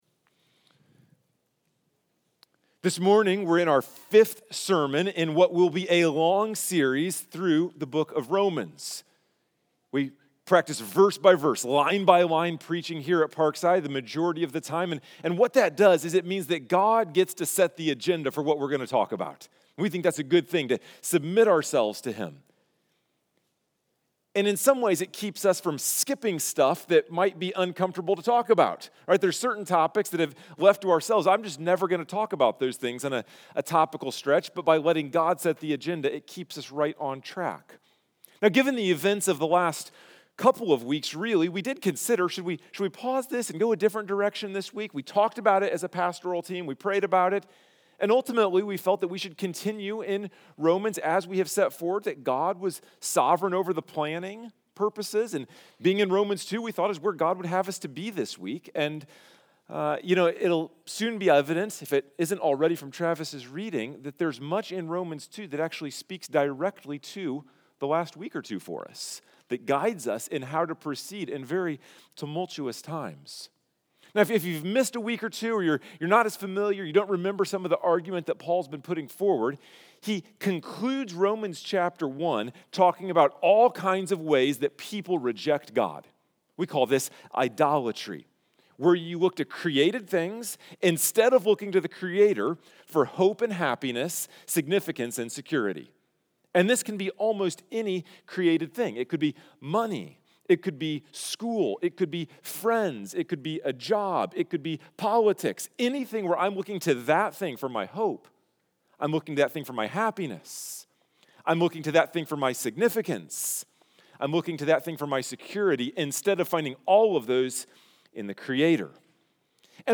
Sunday Morning Sermon from Parkside Bible Church